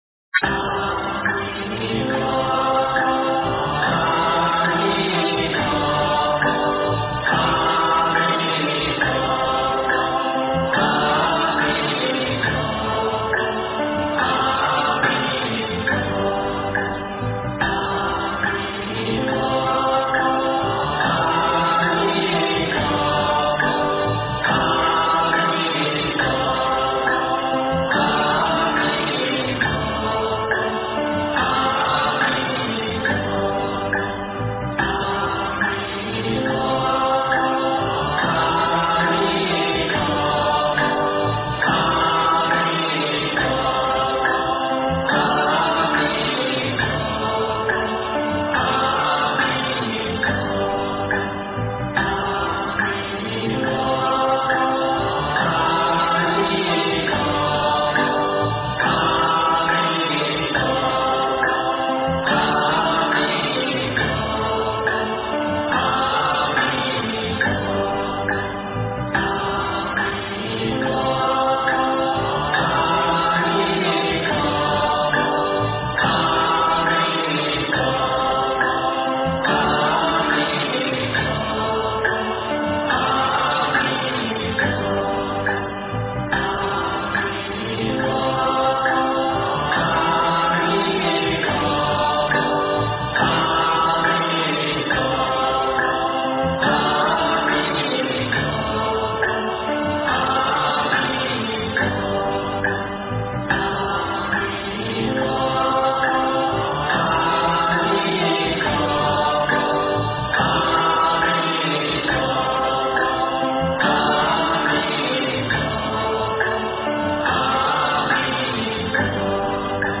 阿弥陀佛--佛经 经忏 阿弥陀佛--佛经 点我： 标签: 佛音 经忏 佛教音乐 返回列表 上一篇： 阿弥陀佛--僧团 下一篇： 南无阿弥陀佛--慧律法师 相关文章 最盛光明自在王--僧团 最盛光明自在王--僧团...